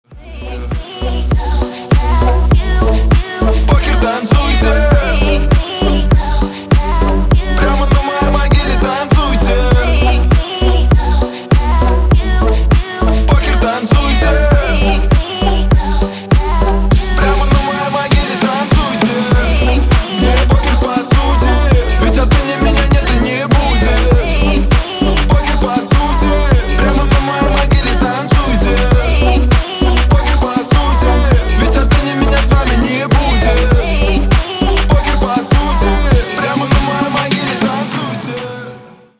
• Качество: 320, Stereo
Хип-хоп
dance